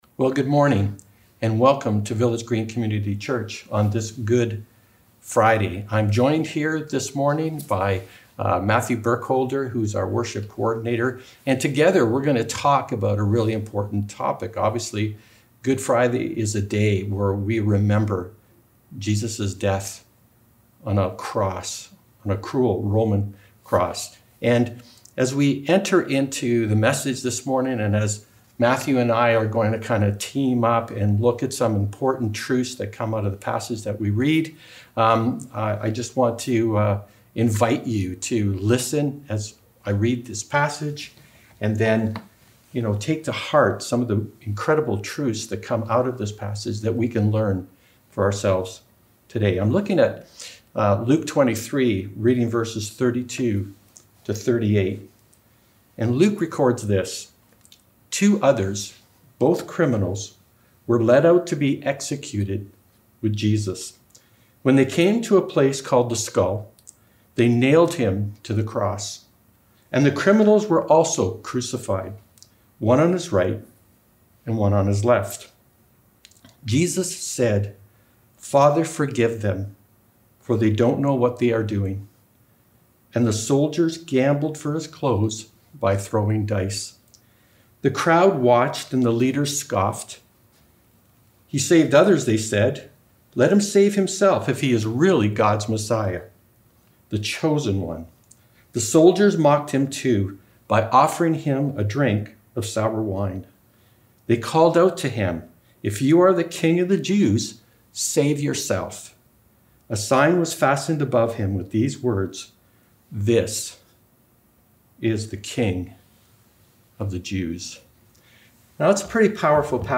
GoodFridayService.mp3